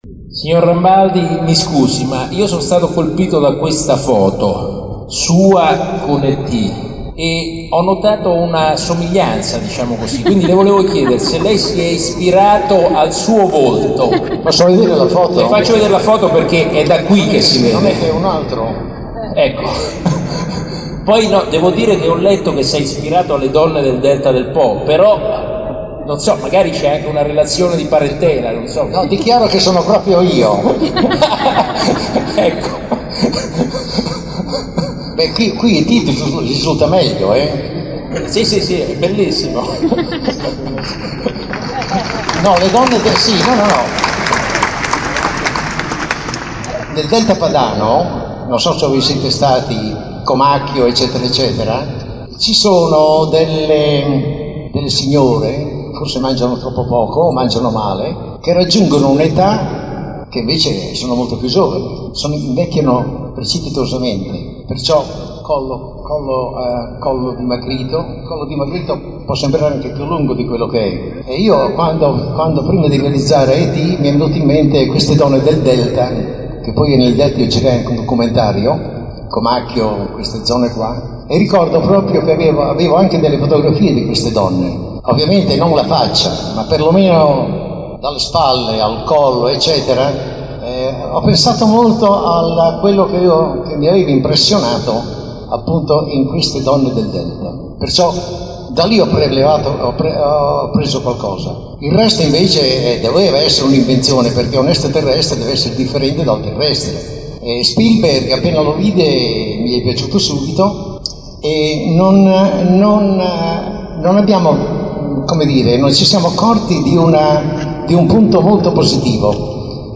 I miei alieni da Oscar - Racconti Anno 2006 - Palazzo Ducale - Sassuolo